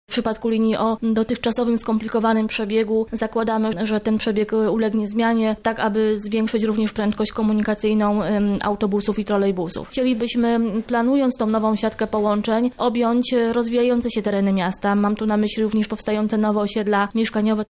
– Trasy niektórych linii zostaną zmienione – dodaje rzeczniczka